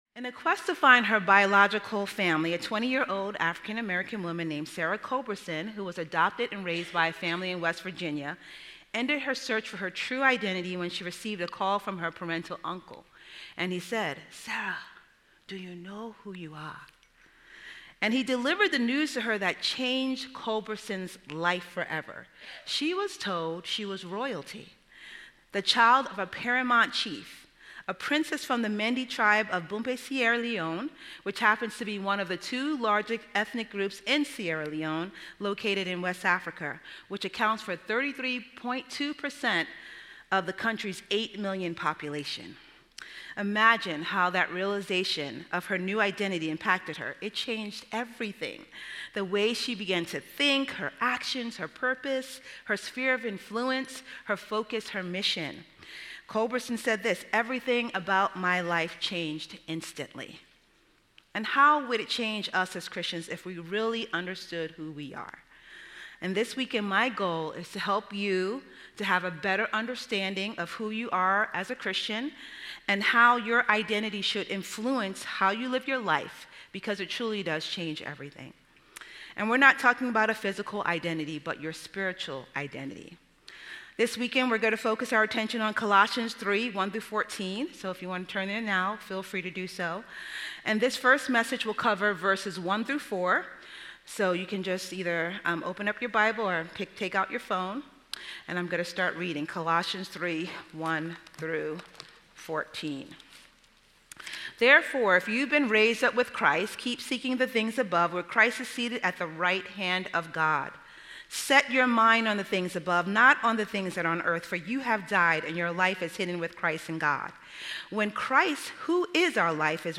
Women Women's Fall Conference - 2025 Audio Video Series List Next ▶ Current 1.